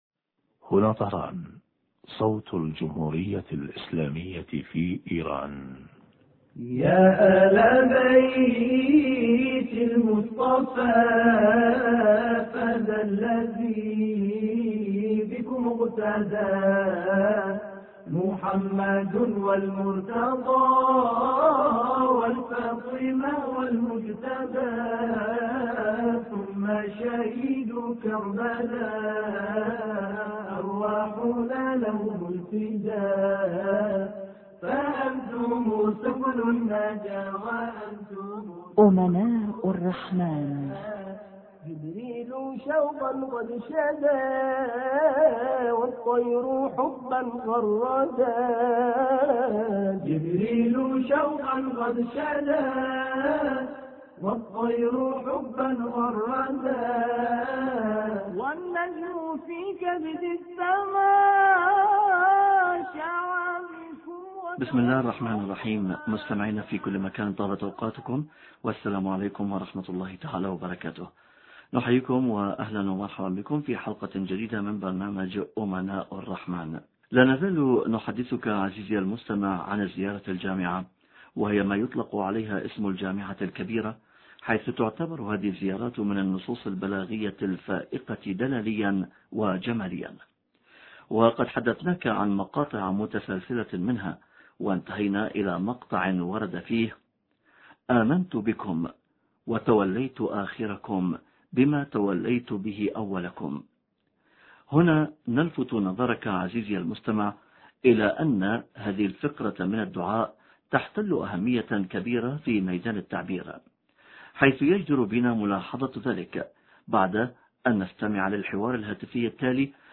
شرح فقرة: آمنت بكم وتوليت أولكم بما توليت به آخركم... حوار